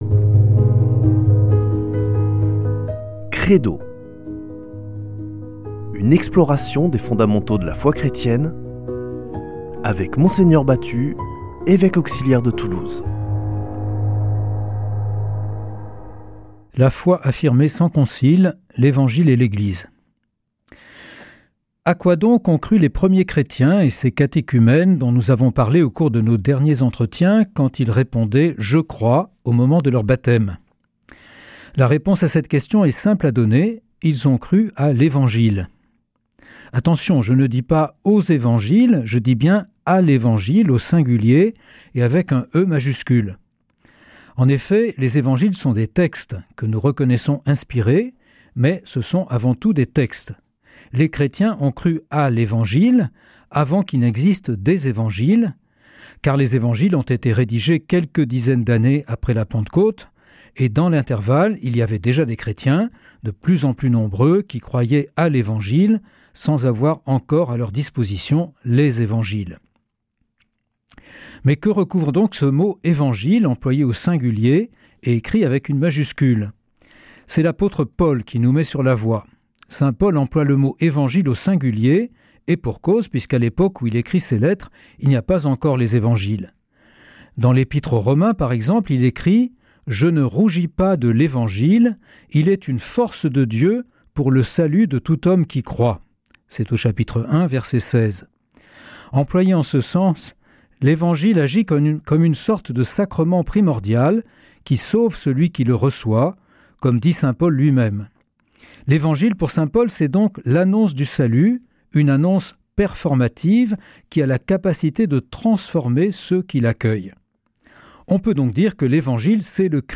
Une émission présentée par
Mgr Jean-Pierre Batut
Evêque auxiliaire de Toulouse